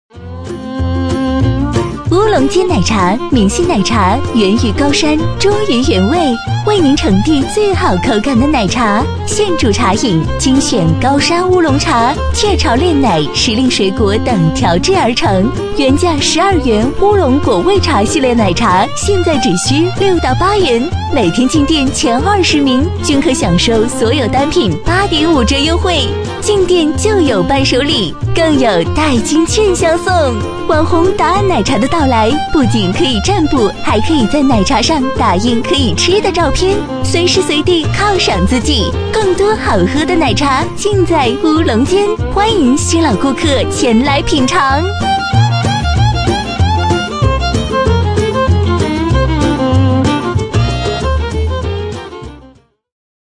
B类女36|【女36号促销】乌龙煎奶茶
【女36号促销】乌龙煎奶茶.mp3